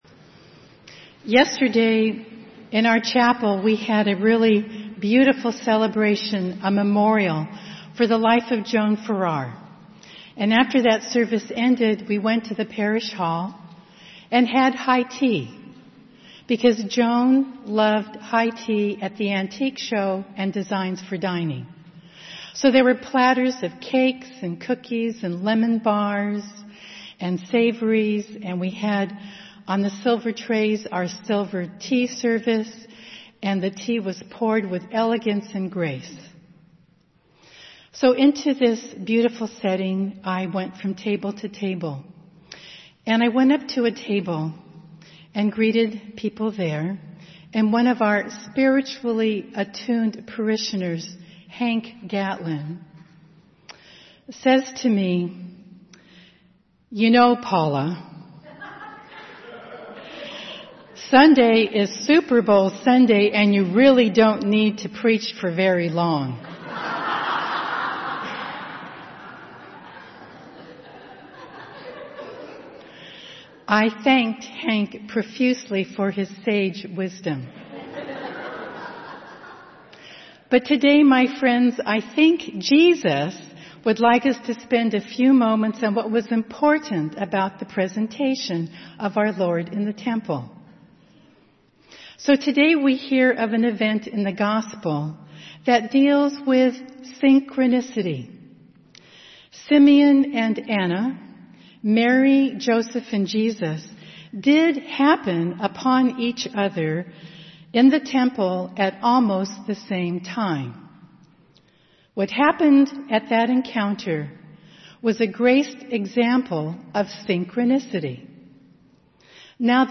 St. Francis Sermons